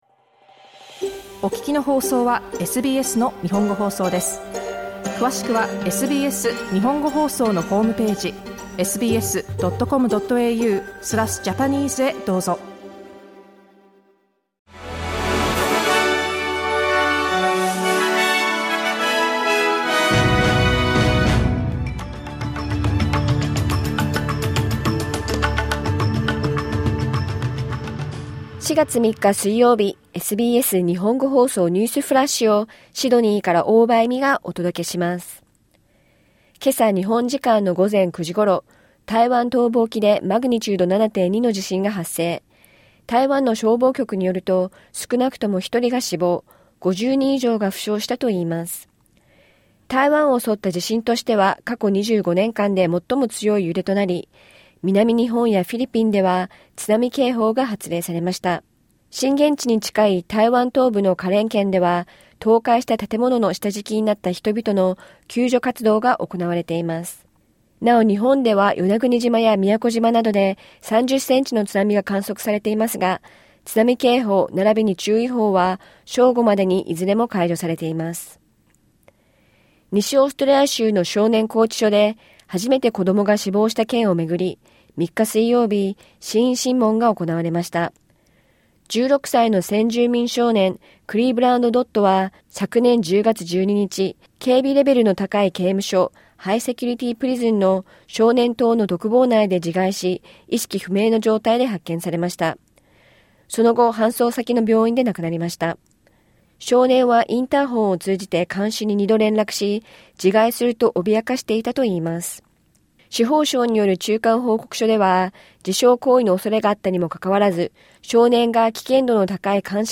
SBS日本語放送ニュースフラッシュ 4月3日水曜日